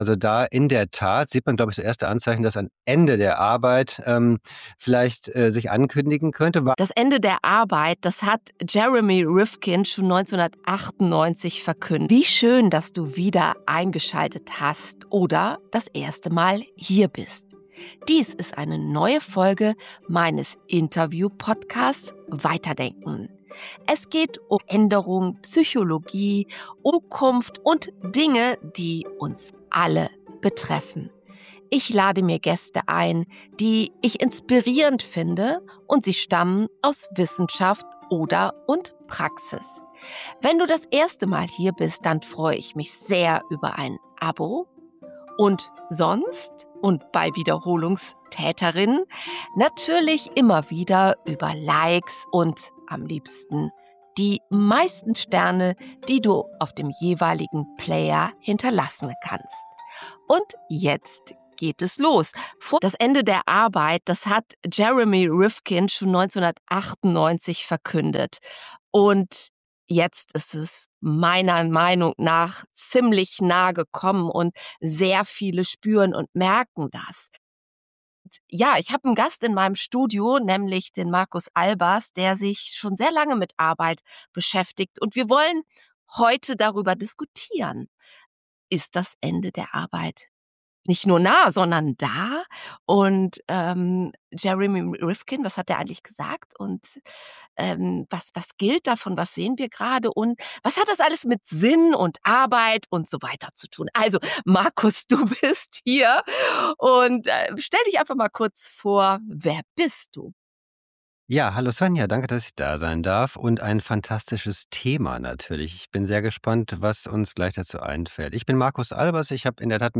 Der Ausgangspunkt dieses Expertengesprächs ist eine Prophezeihung. 1995 sagte der Zukunftsforscher Jeremy Rifkin etwas voraus, das nun für uns alle spürbar Realität wird.